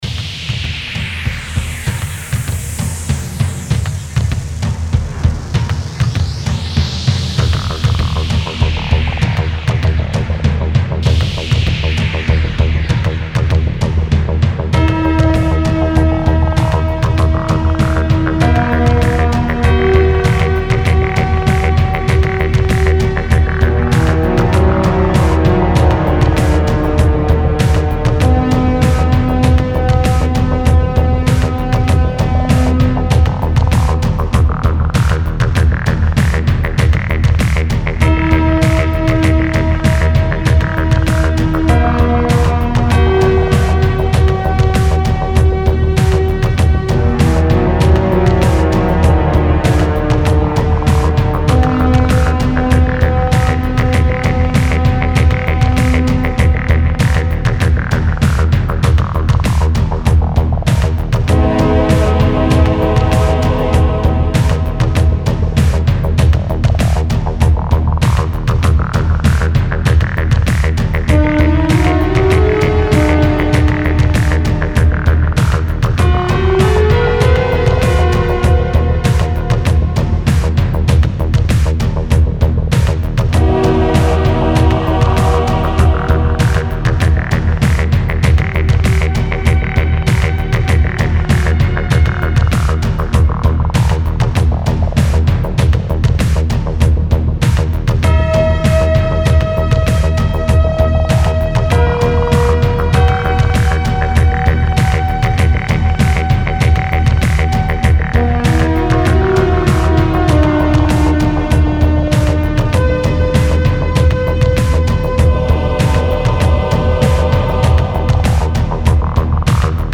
Meditative New Age